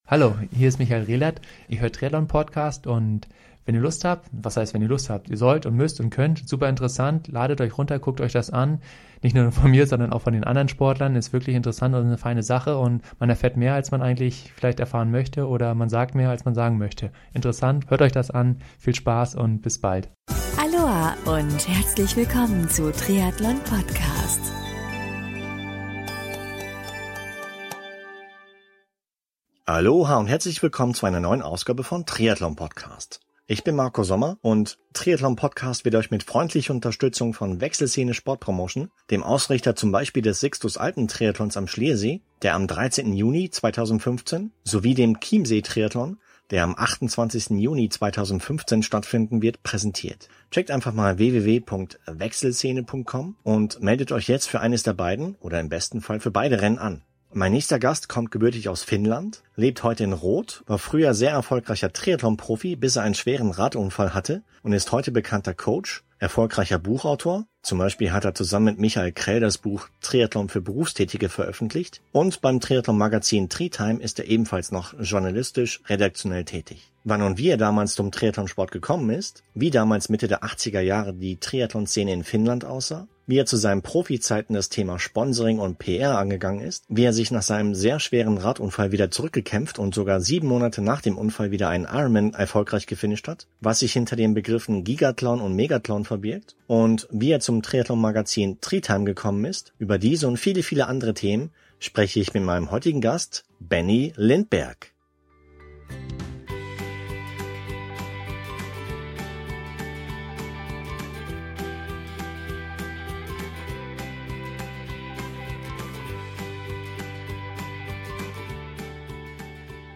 Das Gespräch gibt einen persönlichen und ausführlichen Einblick in meinen Weg.